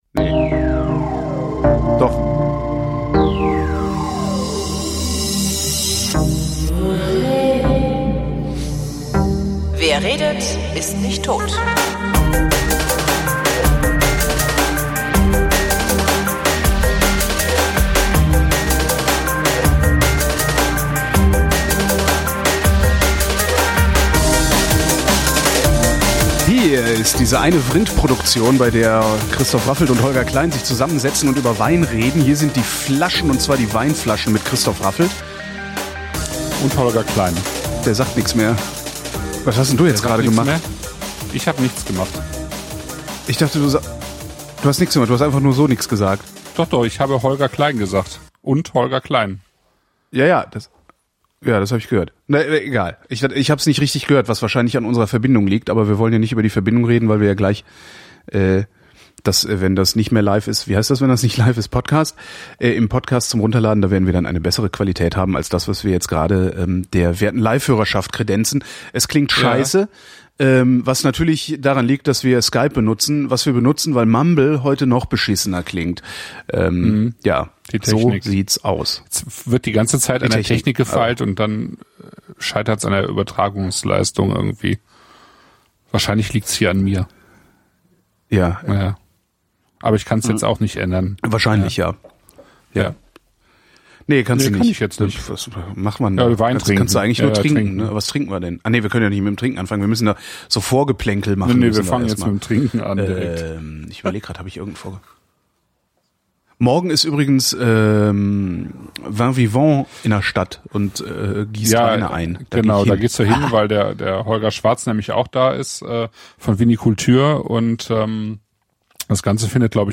Während der Livesendung hatten wir sehr starke Verbindungsprobleme, mit teilweise so absurden Latenzen, dass wir nicht gemerkt hatten, wenn wir durcheinander redeten, so dass ich die beiden Tonspuren, die wir unabhängig voneinander aufgenommen haben, nicht mehr sinnvoll angelegt bekommen habe.